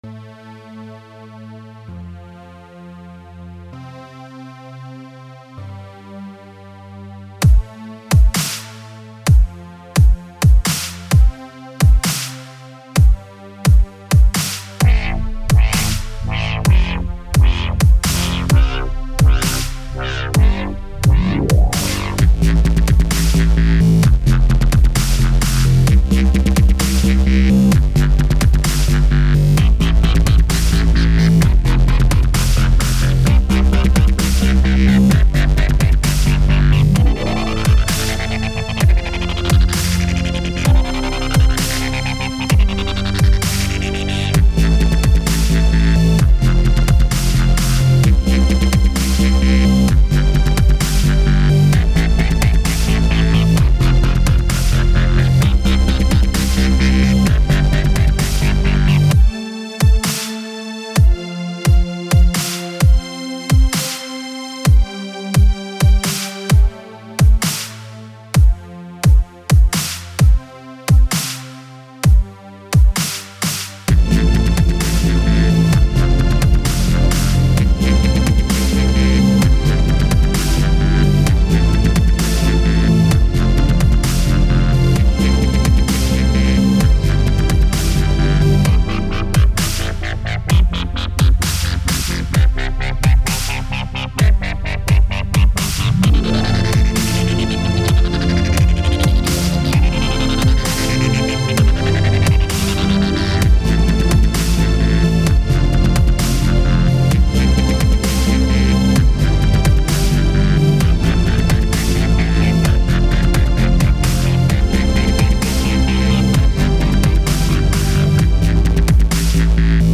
I was fooling around with some synths; i wanted to create some summer dance and ambient beats.
And suddenly it ended up with chillstep ^O,..,o^ Hope you enjoy it ps: let me know, how it sounds on your sound system.
chillstep dubstep
electro synth